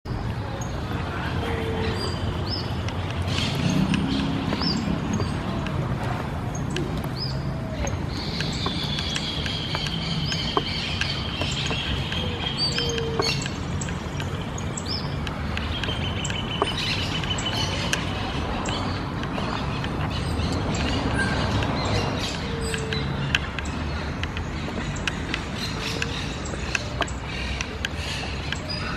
Mosqueta Pico Curvo (Acrochordopus burmeisteri)
Nombre en inglés: Rough-legged Tyrannulet
Fase de la vida: Adulto
Localización detallada: Jardín Botánico
Condición: Silvestre
Certeza: Vocalización Grabada